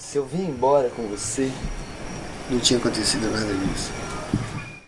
Tag: 场记录 mzr50 ecm907 话音 语音 聊天 雄性